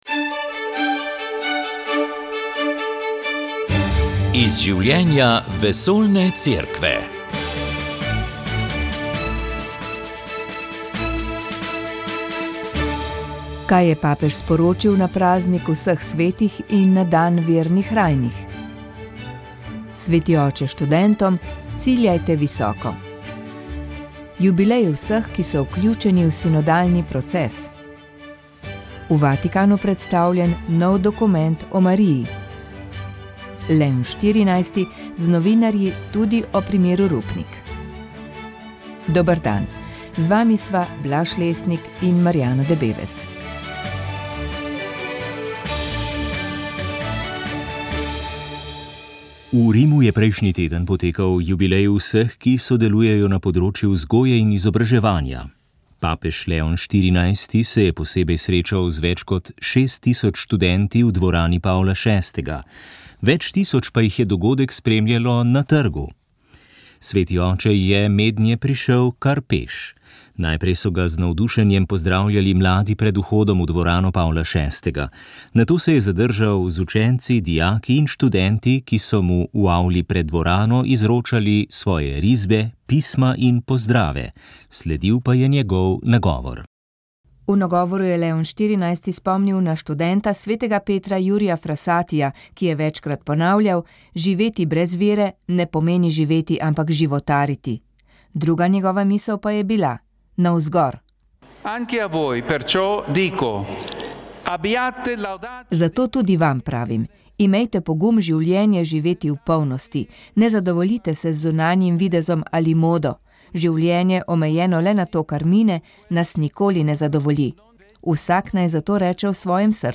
Na Radiu Ognjišče ste lahko prisluhnili prvemu predvolilnemu soočenju.
V uro in pol trajajoči razpravi smo odprli vprašanja, ki se jih drugi mediji večinoma ne lotijo. Predstavniki povabljenih strank so odgovarjali na vprašanja s področij demografije, oskrbe starejših, migracij, varnosti, obrambe in vojne v Ukrajini.